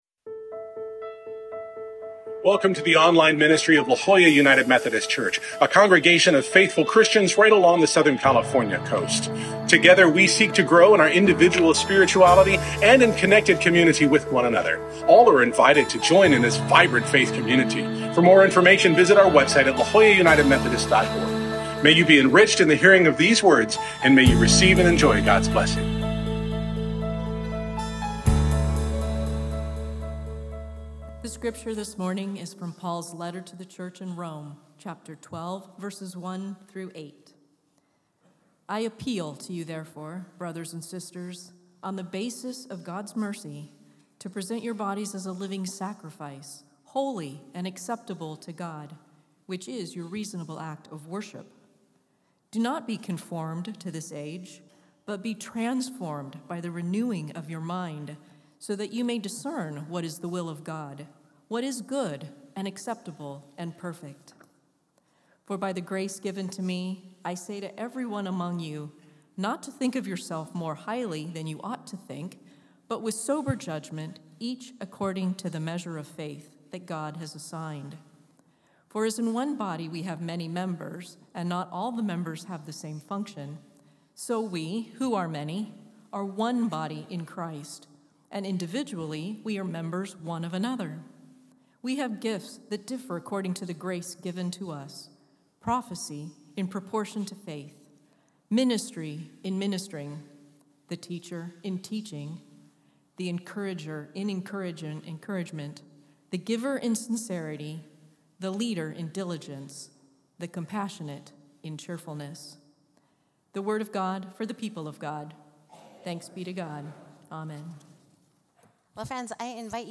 Full Worship Video